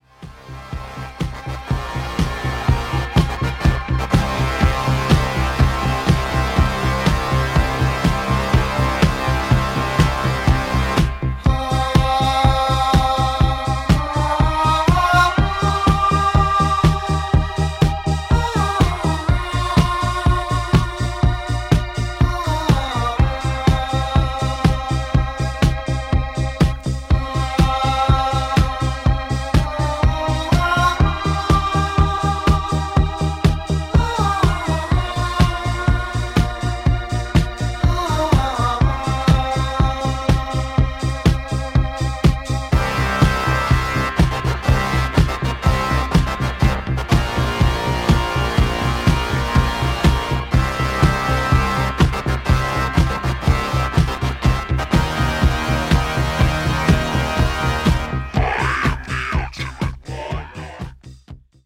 Recorded at The Old Smithy / Worechester in 1978
Vocals, Guitars, Keyboards, Percussion
Backing Vocals